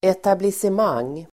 Uttal: [etablisem'ang:]